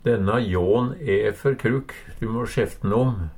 krukk - Numedalsmål (en-US)